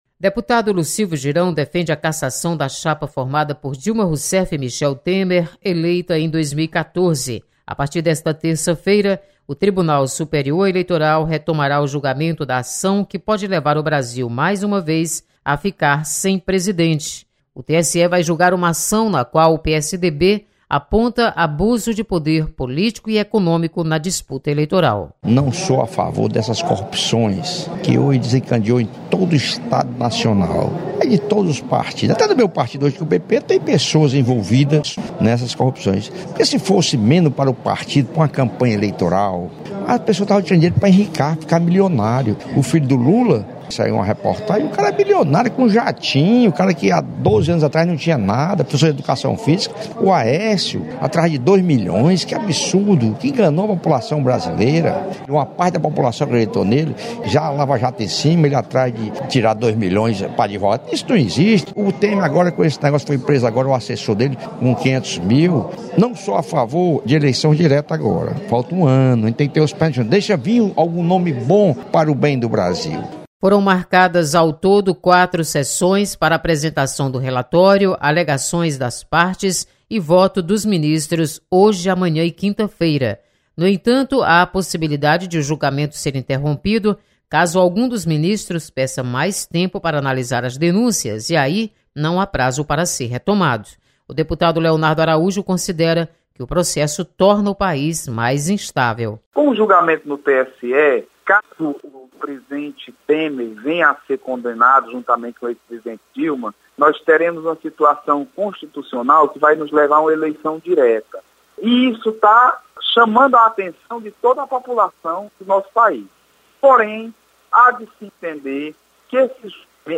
Deputados comentam sobre processo de cassação da chapa Dilma/Temer que está sendo julgado no TSE.